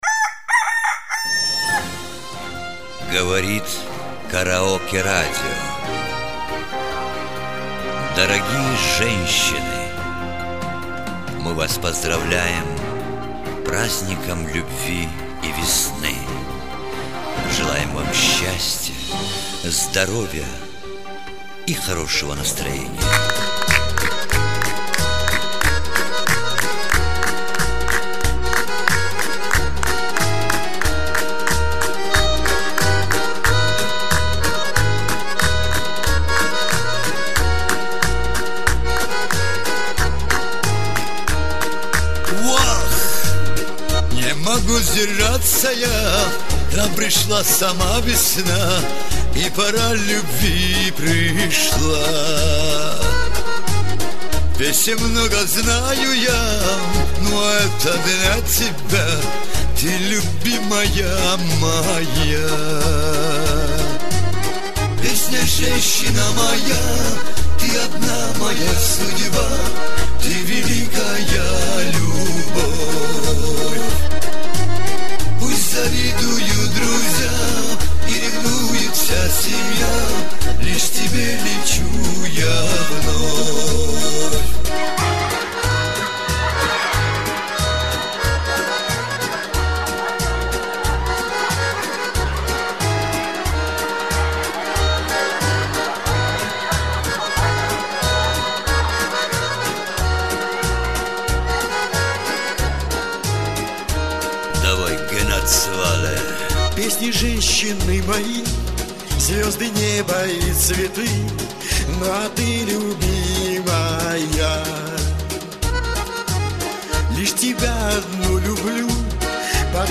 (запись с КАРРАДИО - Москва)
Колоритно, душевно, восхитительно...